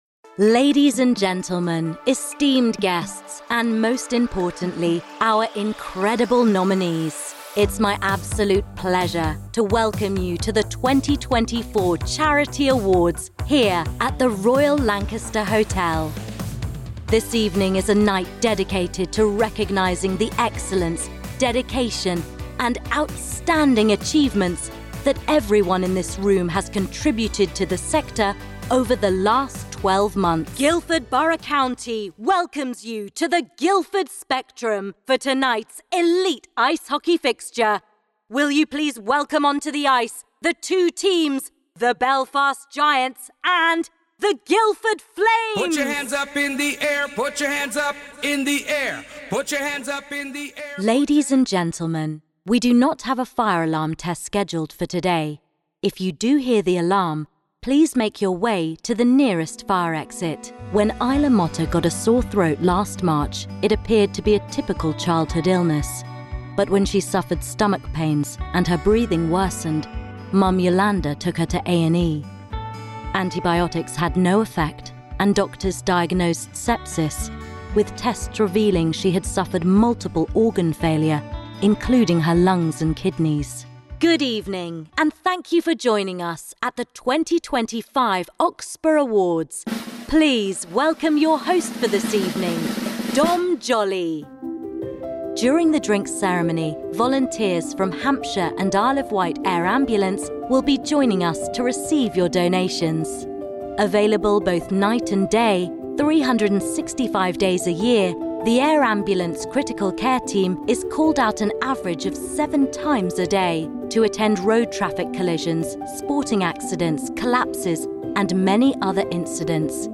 Professional British Voiceover Artist & live event Voice of God with a clear, bright and reassuring voice
Sprechprobe: Sonstiges (Muttersprache):
She has a naturally modern RP accent and plays in the Teens - 30s age range.